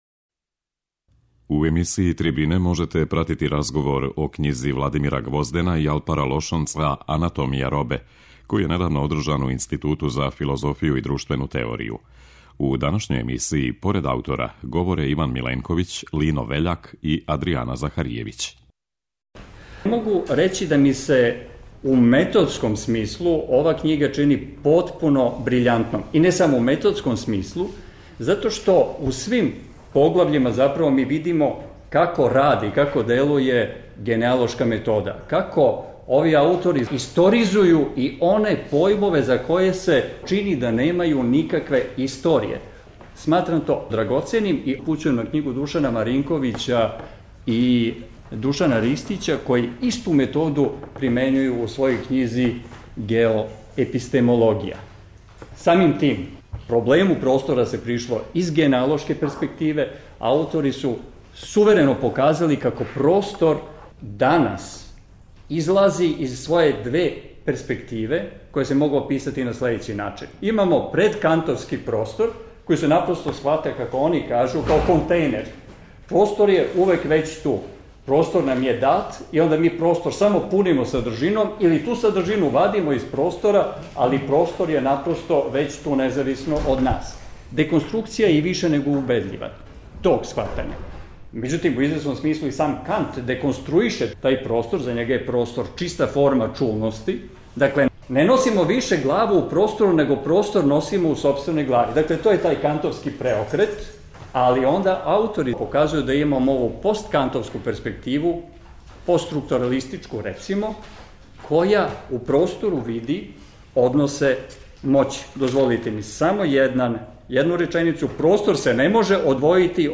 Трибине
У разговору о књизи, поред самих аутора, учествује и група новосадских и београдских филозофа и теоретичари углавном млађе и средње генерације.
преузми : 10.24 MB Трибине и Научни скупови Autor: Редакција Преносимо излагања са научних конференција и трибина.